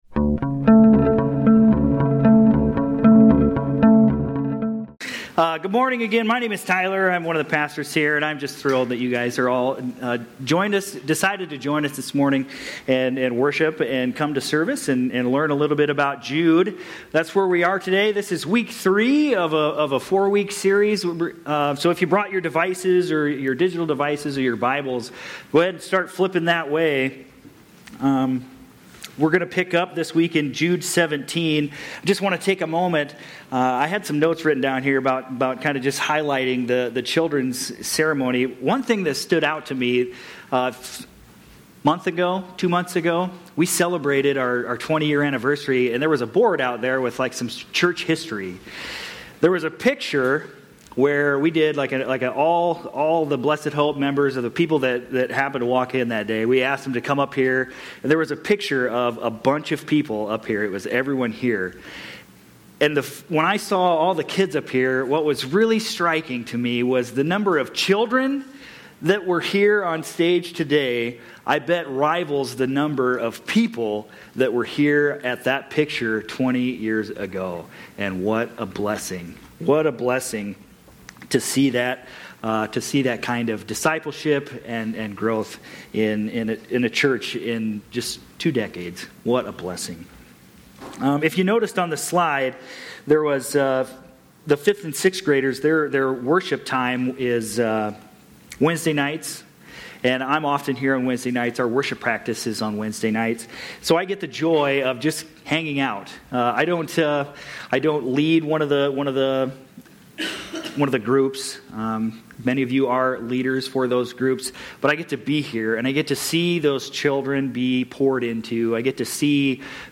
Aug-24-25-Sermon-Audio.mp3